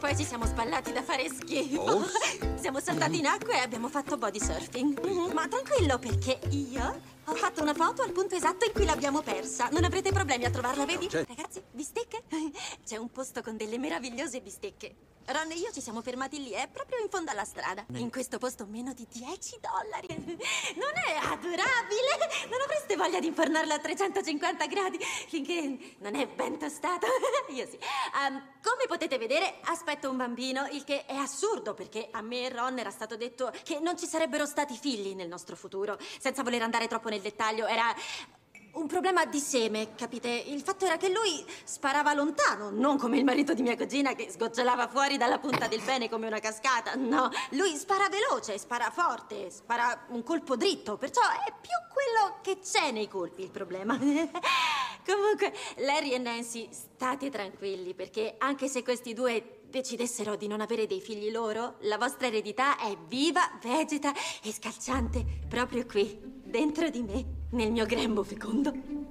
nel film "Gli amici delle vacanze", in cui doppia Meredith Hagner.